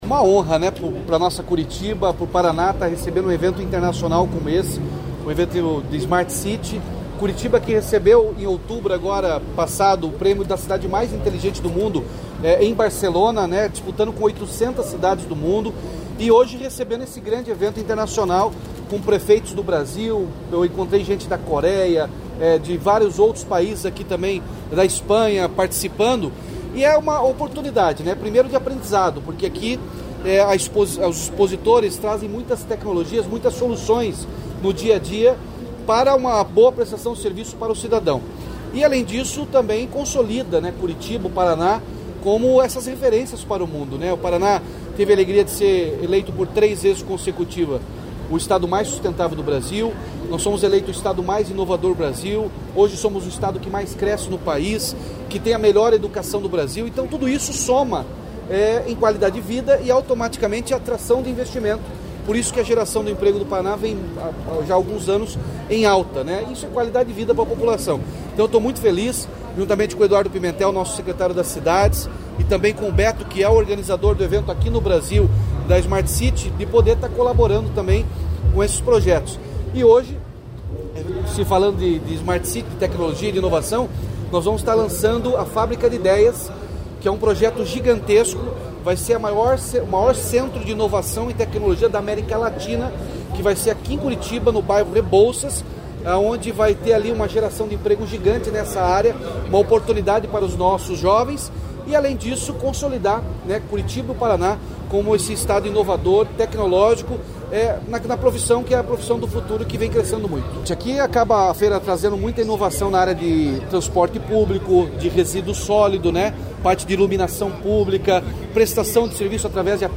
Sonora do governador Ratinho Junior sobre a abertura do Smart City Expo Curiitba 2024